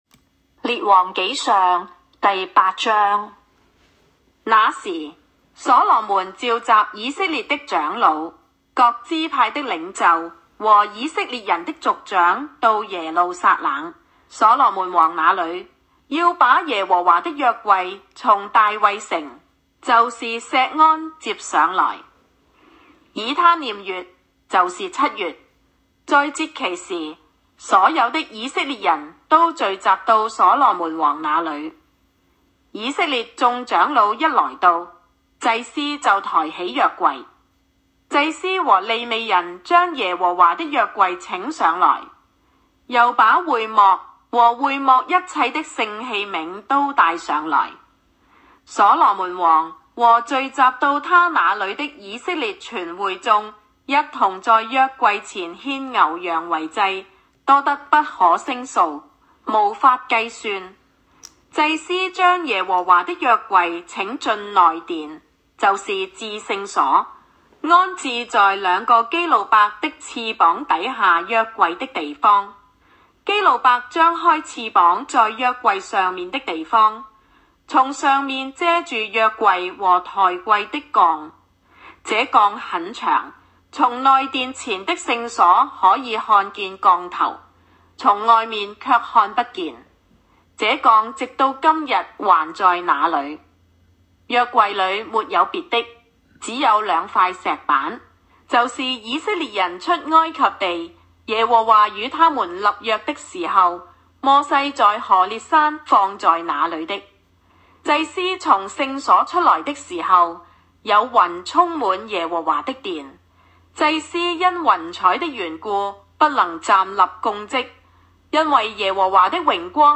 王上08（经文-粤）.m4a